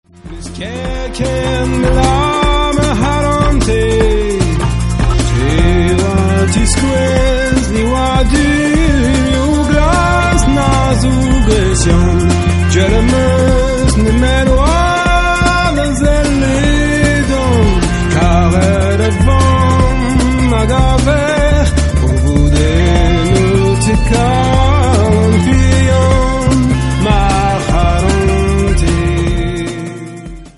MP3 64kbps-Stereo